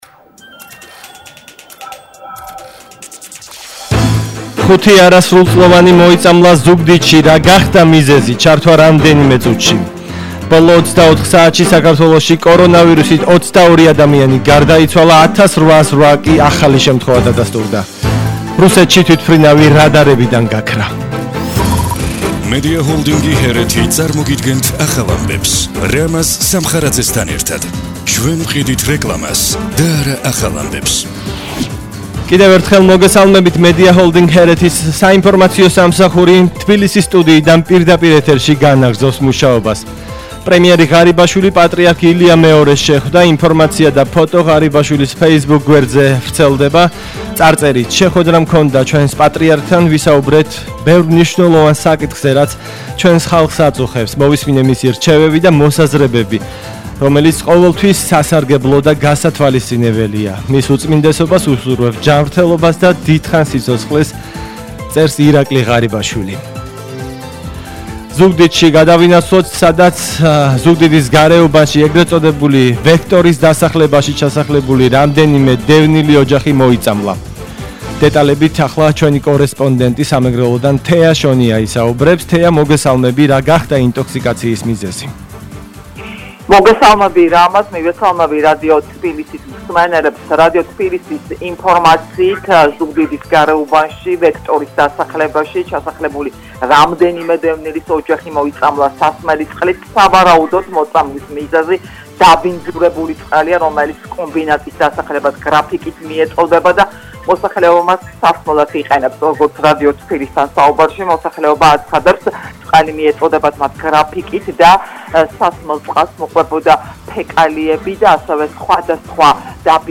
ახალი ამბები 15:00 საათზე –17/07/21 – HeretiFM